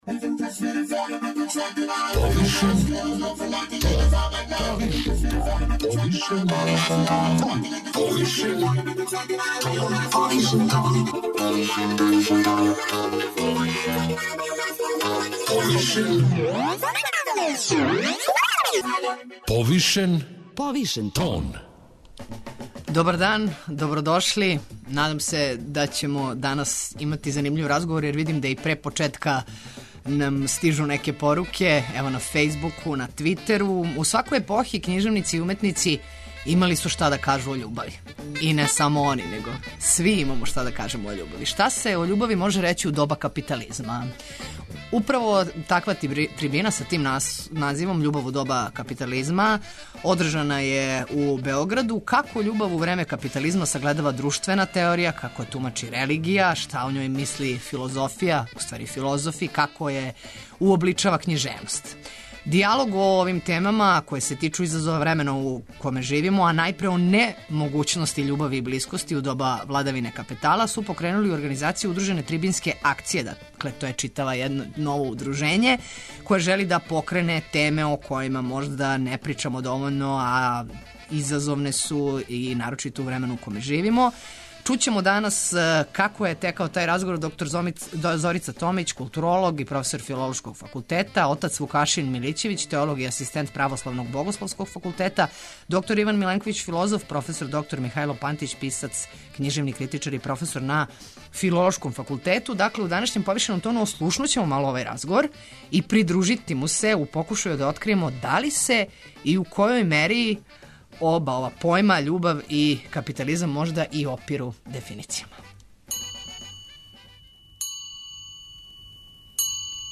У данашњем "Повишеном тону" ослушнућемо овај разговор и придружити му се у покушају да откријемо да ли се и у којој мери оба појма, љубав и капитализам опиру дефиницијама?